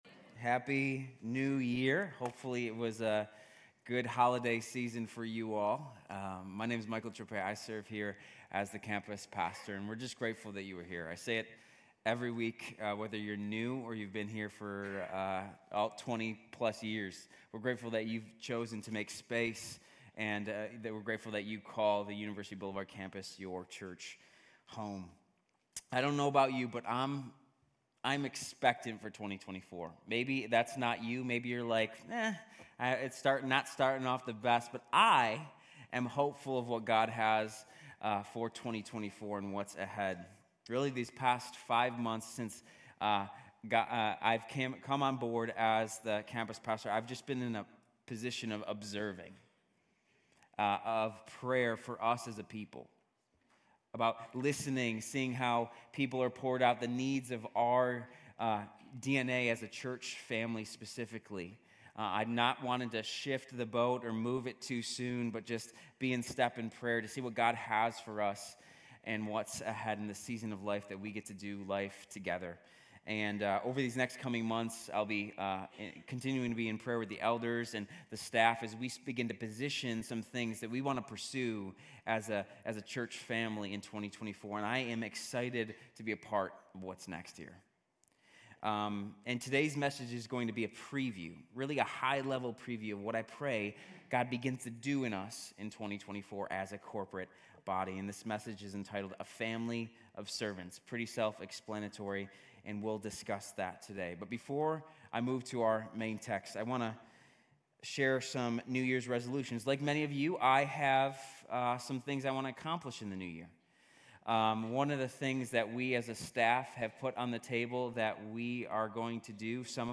Grace Community Church University Blvd Campus Sermons Missional Alignment on Relational Evangelism Jan 08 2024 | 00:34:31 1x 00:00 / 00:34:31 Subscribe Share RSS Feed Share Link Embed